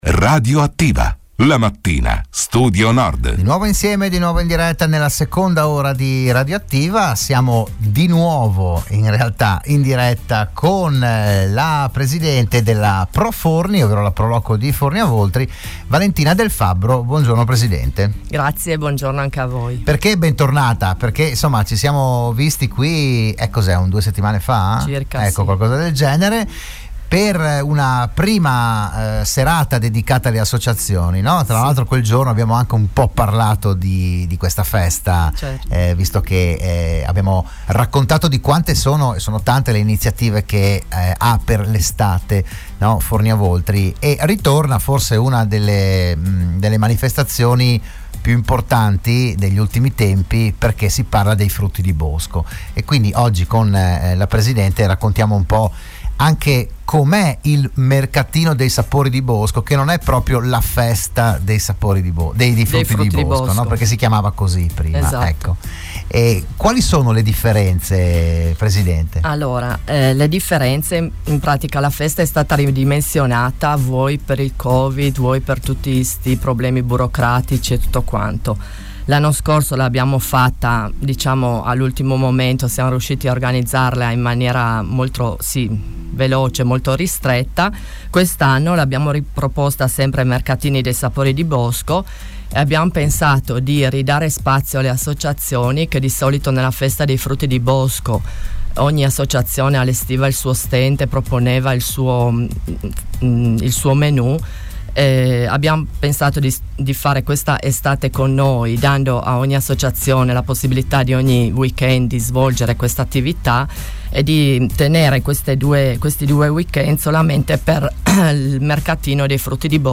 Dell’iniziativa si è parlato a Radio Studio Nord